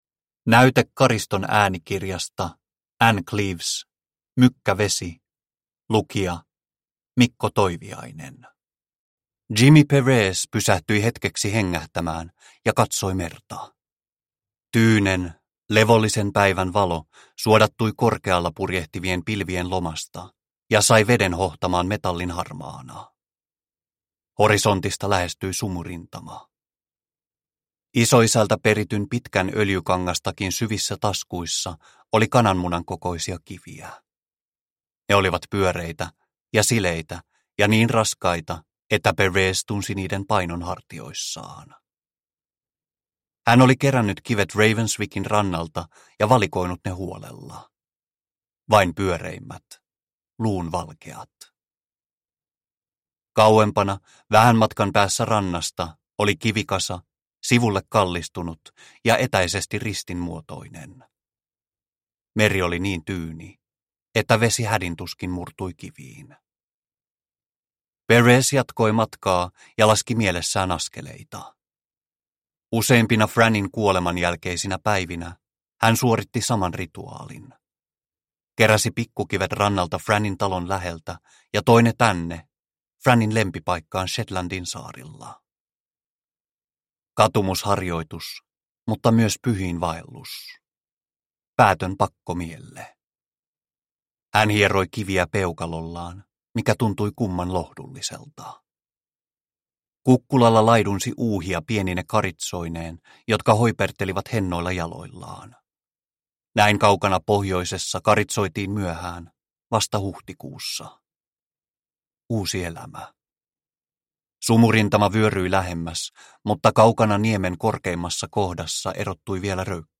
Mykkä vesi – Ljudbok – Laddas ner